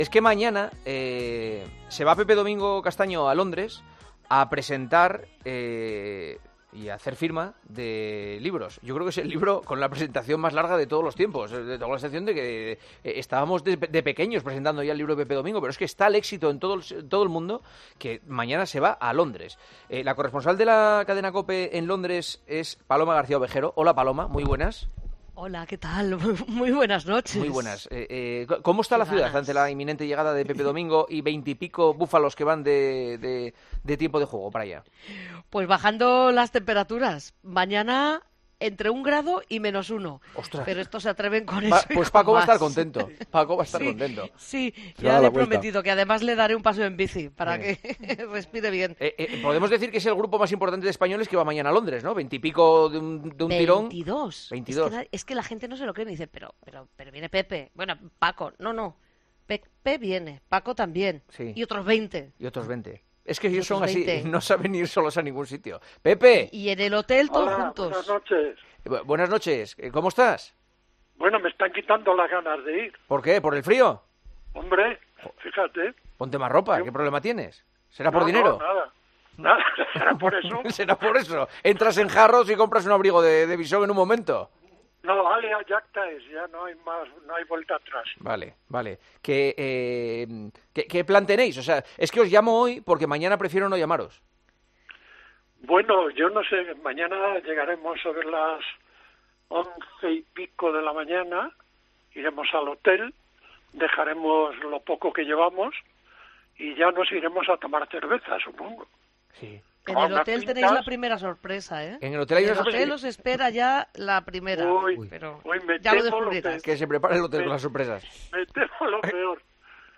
Pepe Domingo Castaño y la corresponsal Paloma García Ovejero cuentan los planes del equipo de Tiempo de Juego para este jueves en Londres, donde Pepe firmará su libro.